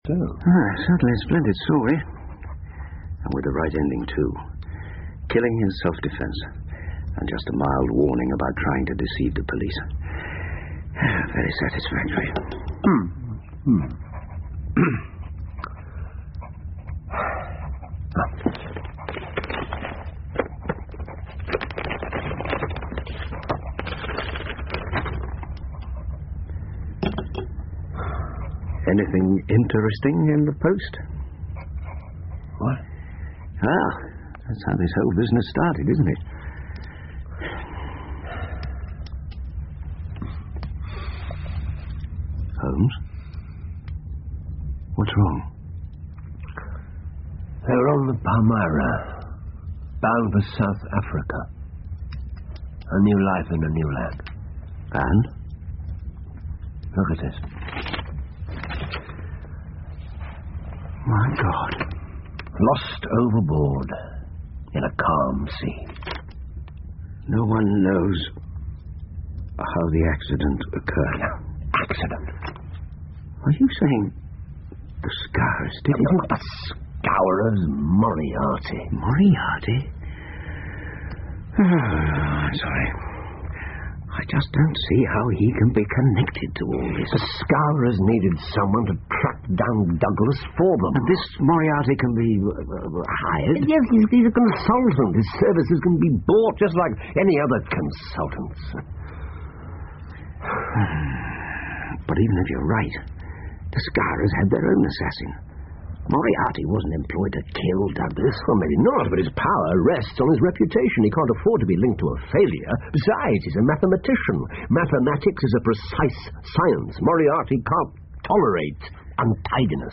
福尔摩斯广播剧 The Valley Of Fear - Part 02-11 听力文件下载—在线英语听力室